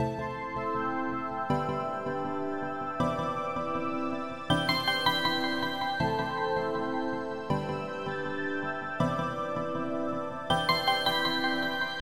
描述：老鼠夹断裂。
标签： 奶酪 鼠标 哎哟 捕捉 陷阱
声道立体声